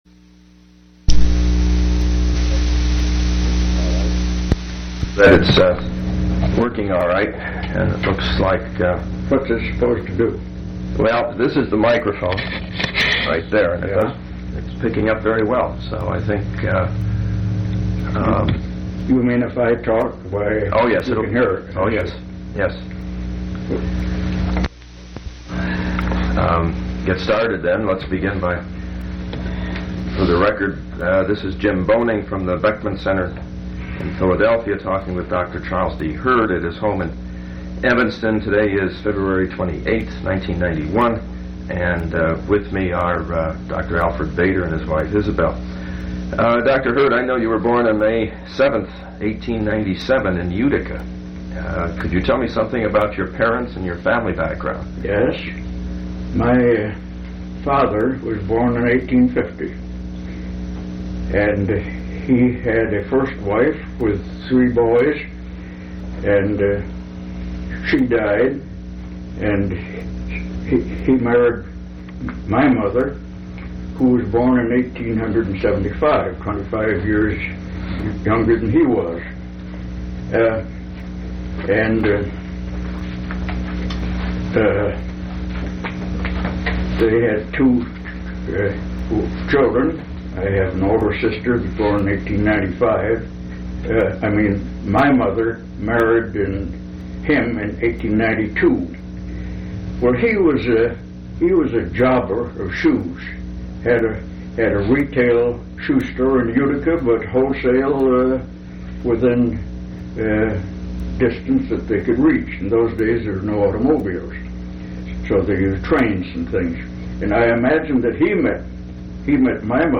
Complete Interview Audio File